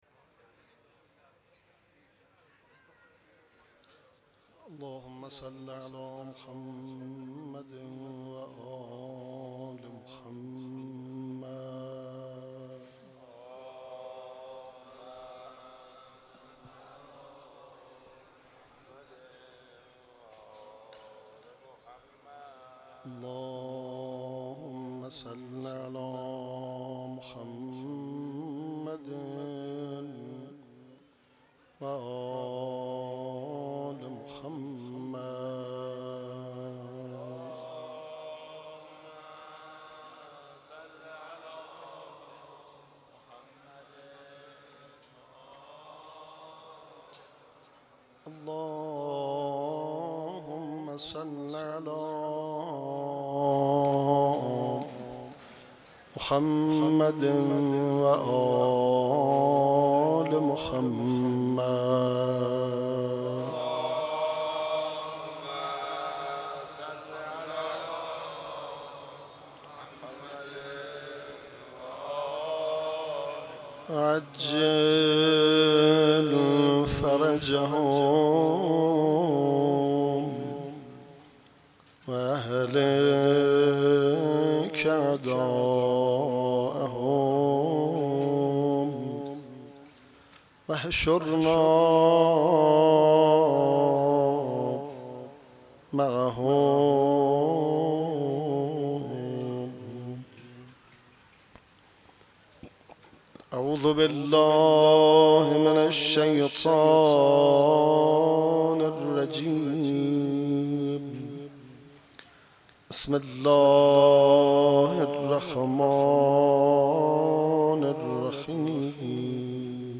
شب دوم ماه رمضان
قرائت مناجات شعبانیه ، روضه حضرت عباس (علیه السلام)